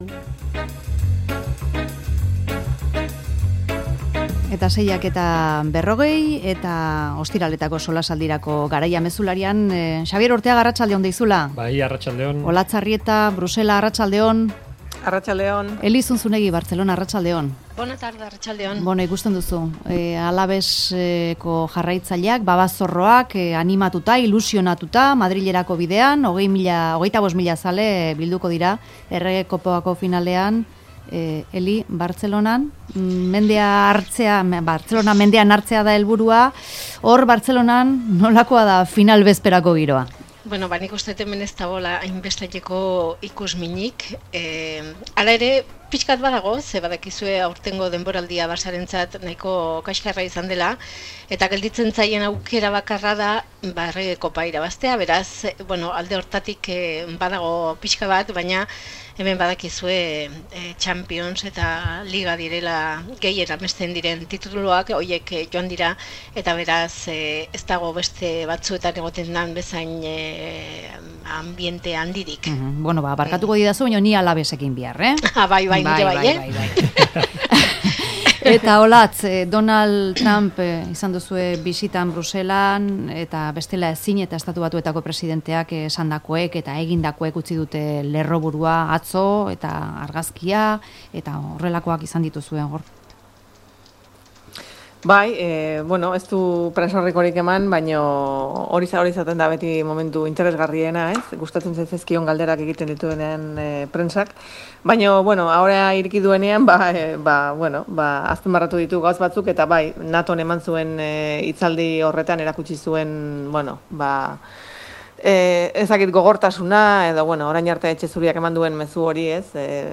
Mezularia|Solasaldia